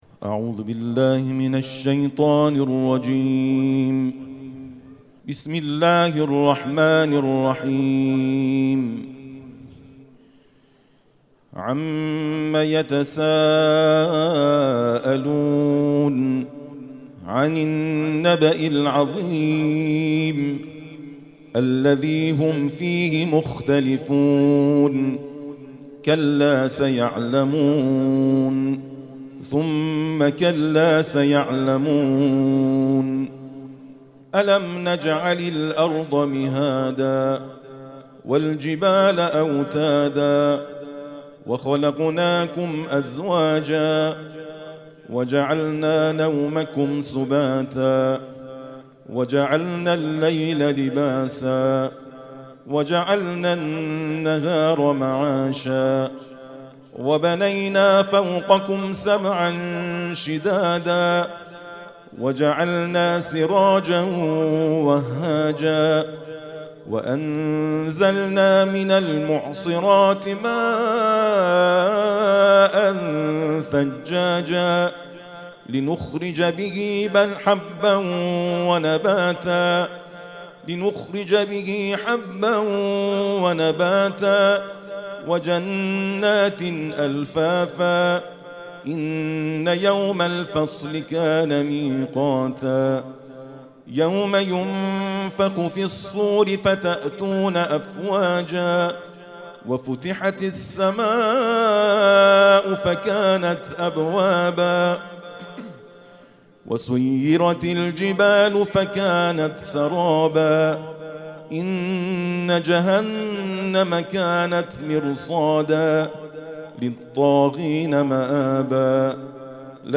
ترتیل خوانی عمومی